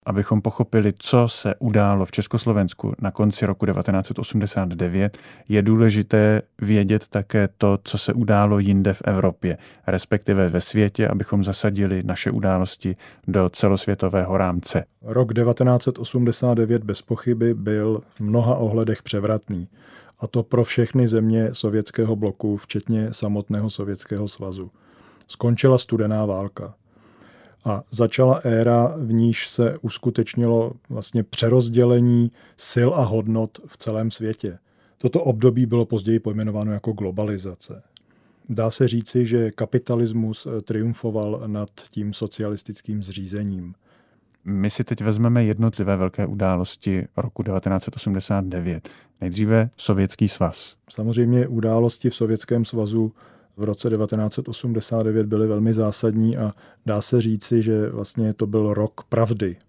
Audiokniha
Toto CD se zabývá den po dni, hodinu po hodině okolnostmi pádu totality koncem roku 1989. Díky nahrávkám zákulisních jednání s komunistickou mocí budete mimo jiné svědky rozčílení komunistického premiéra Ladislava Adamce i jeho taktické snahy stát se novým prezidentem, vypískání městského tajemníka KSČ Miroslava Štěpána z ČKD ve Vysočanech nebo slov Václava Havla o tom, že nechce být prezidentem. Řada nahrávek nebyla ve zvukové podobě nikdy publikována.